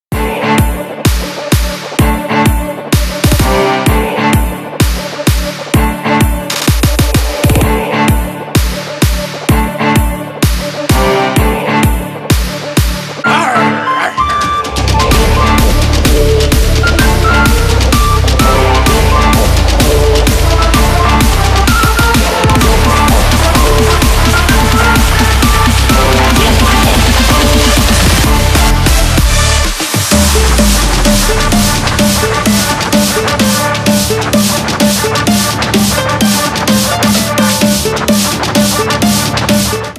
громкие
Electronic
EDM
Big Room
electro house
эпичные
Стиль: big room